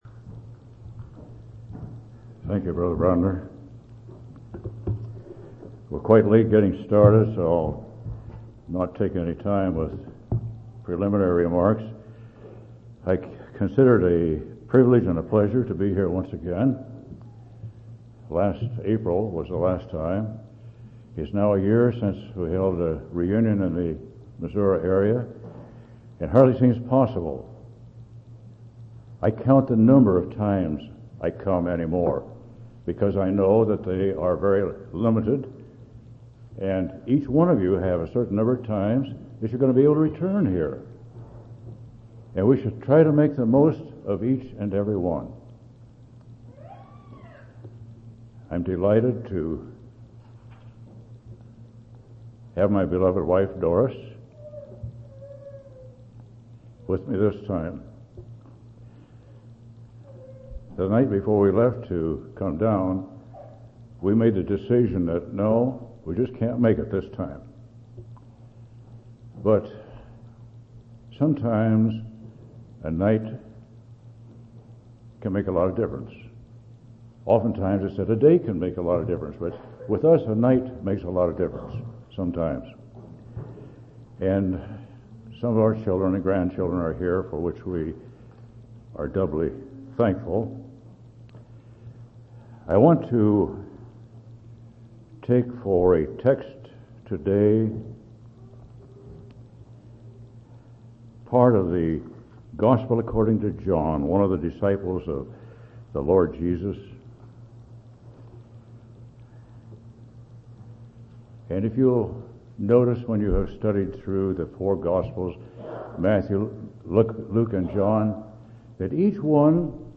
8/12/1995 Location: Missouri Reunion Event